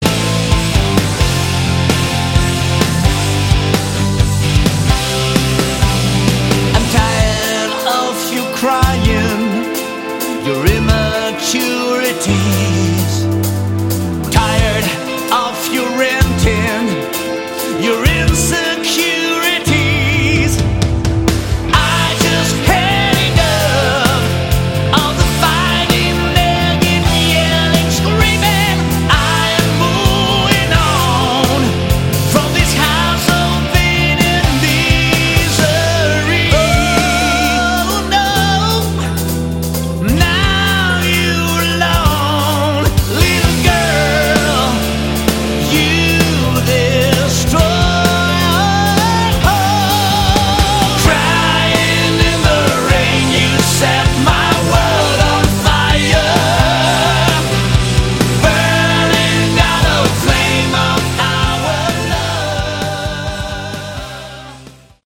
Category: Hard Rock
bass
lead guitar
rhythm guitar
keyboards
drums
lead vocals